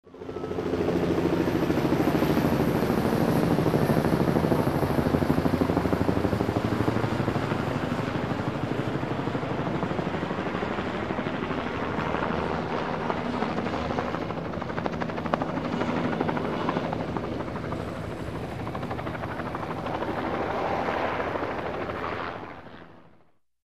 Звуки вертолетов